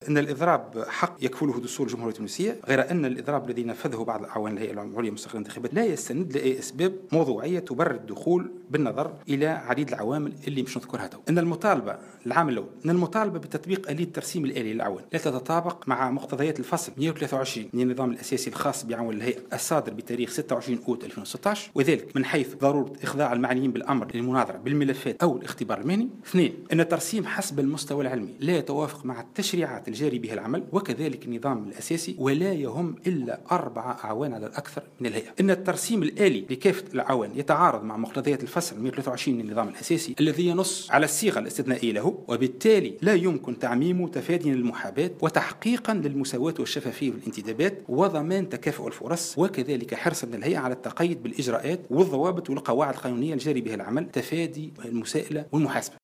وقال في تصريح لمراسل "الجوهرة أف أم" إن الاضراب الذي ينفذه نحو 80 عاملا يعتبر الأول من نوعه بمؤسسة مازالت في طور التأسيس.